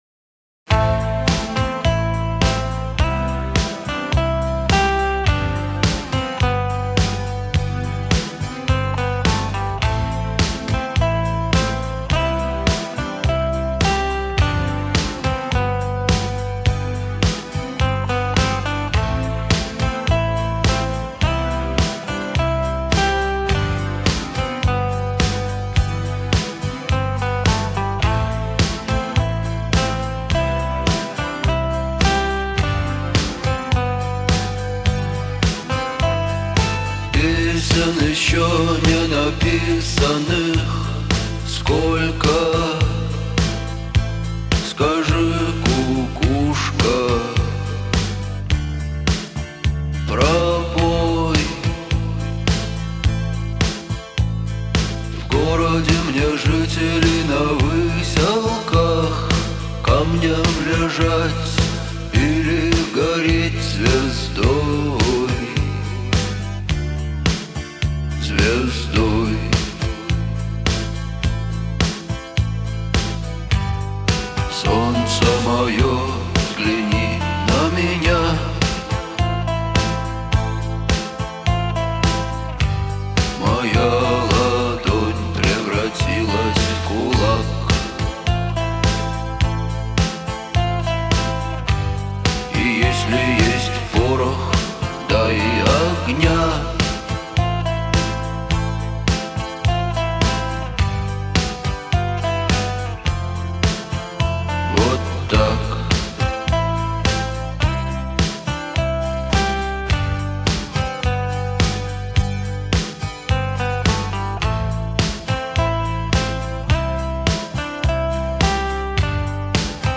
• Жанр: Русские песни / Ремиксы